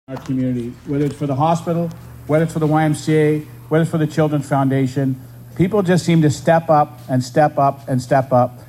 The second annual YMCA of Central East Ontario (YMCA of CEO) Night in White Garden Party was held at Connon Nurseries on Friday evening.